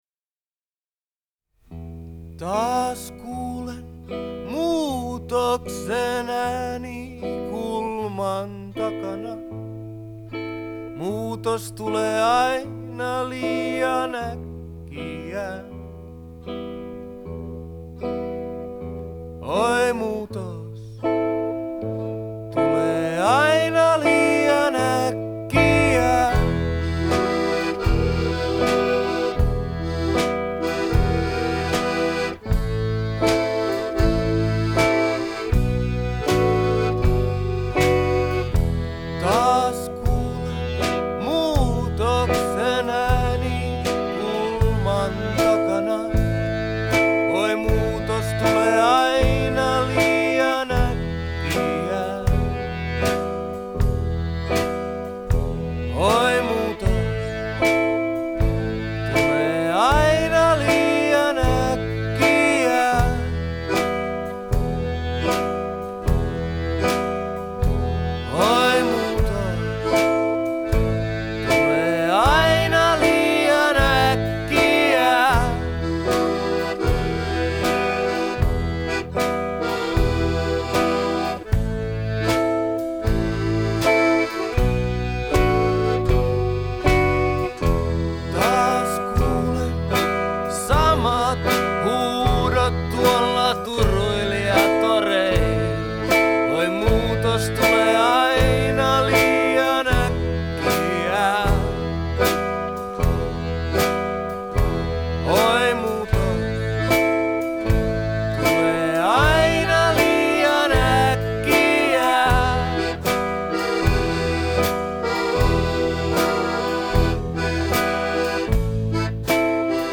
вокал и гитара
аккордеон и вокал
- барабаны
Genre: Folk, World